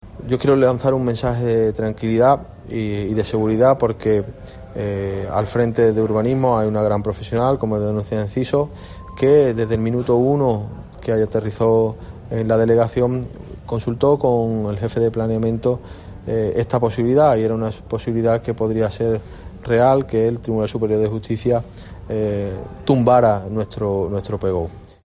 El alcalde de El Puerto, Germán Beardo, sobre la sentencia del TSJA contra el PGOU